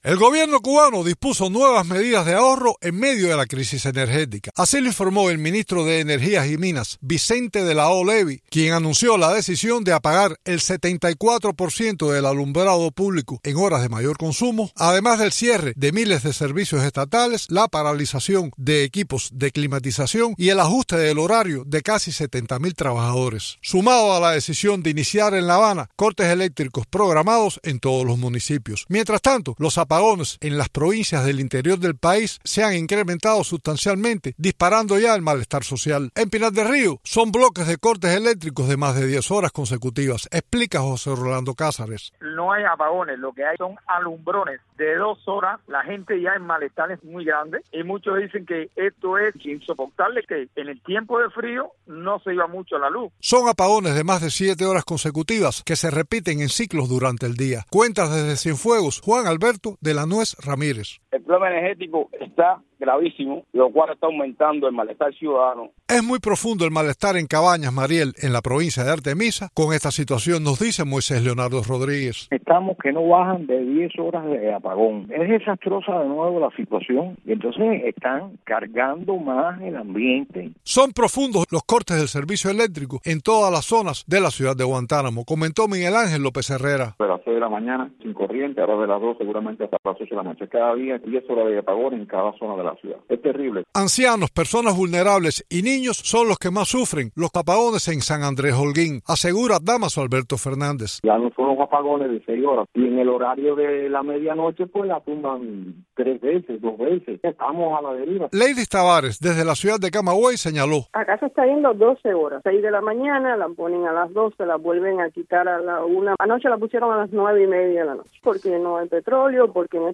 Cubanos describen la crisis con los apagones y el aumento del malestar ciudadano